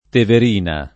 tever&na] top. — T. o Val T., tratto della media valle del Tevere tra Orvieto e Orte — anche nel nome dei comuni di Castiglione in T., Bassano in T., Penna in T., Lugnano in T. — cfr. tiberino